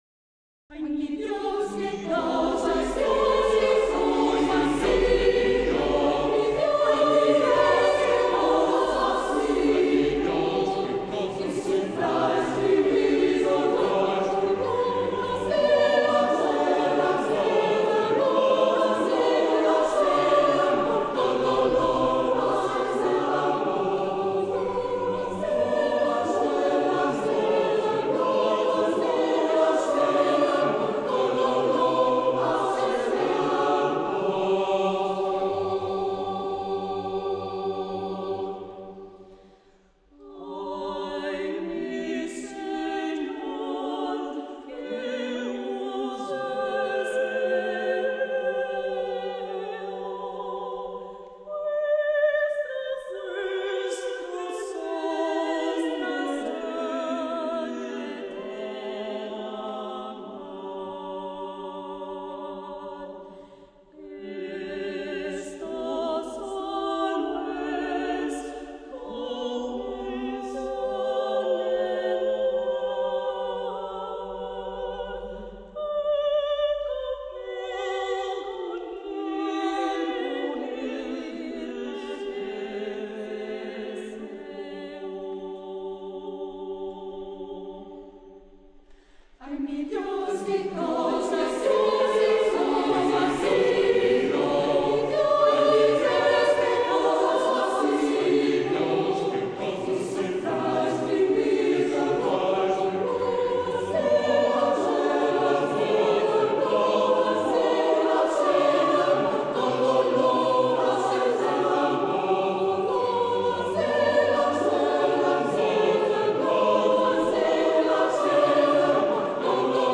Algumas obras gravadas ao vivo